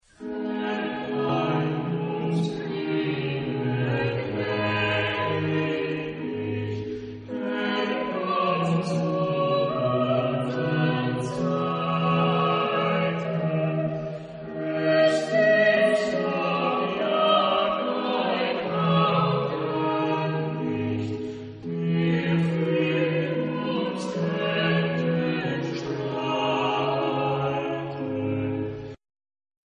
Epoque: 18th century
Genre-Style-Form: Chorale ; Sacred
Type of Choir: SATB  (4 mixed voices )
Instruments: Organ (ad lib)
Tonality: C major ; A minor